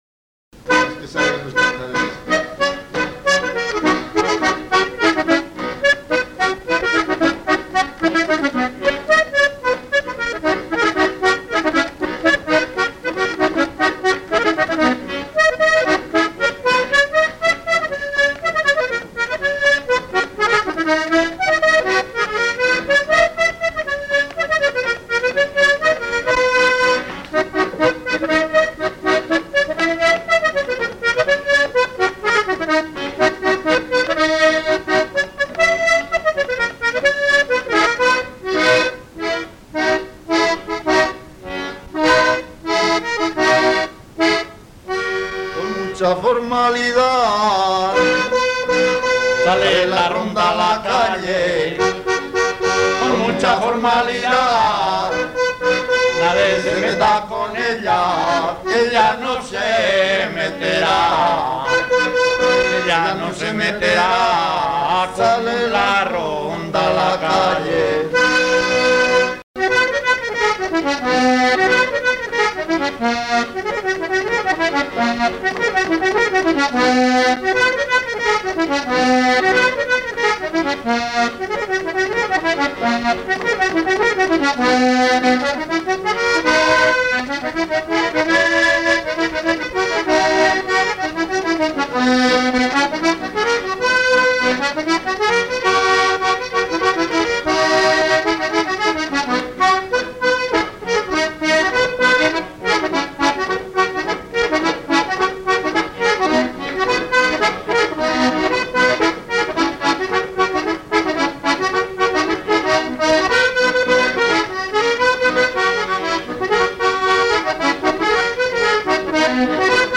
Título: Jotas de ronda V, con acordeón
Clasificación: Cancionero
al acordeón
Lugar y fecha de grabación: Nieva de Cameros, 7 de julio de 1996
El sonido potente del acordeón, que conjuga melodía, acordes y bajos en manos de los buenos intérpretes, se acopló a las rondas nocturnas de los mozos, sustituyendo en muchos casos a los instrumentos de cuerda.